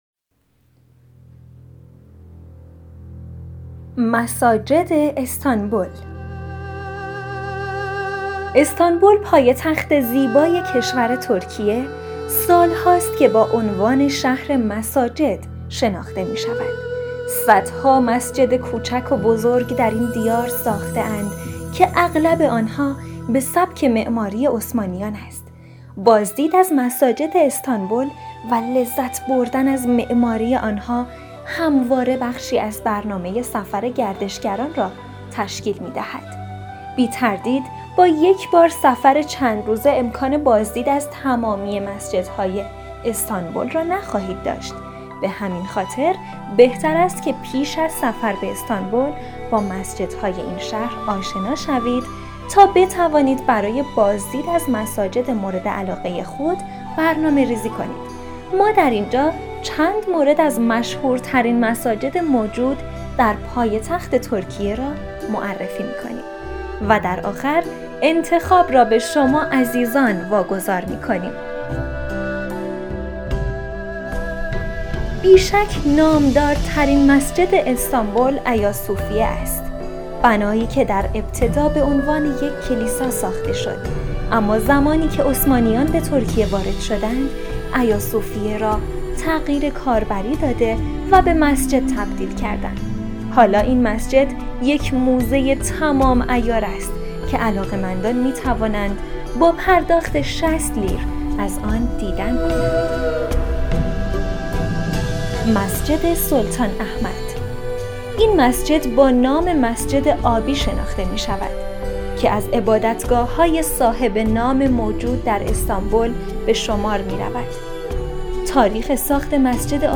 مساجد استانبول | راهنمای صوتی مساجد استانبول | رادیو فاینداتور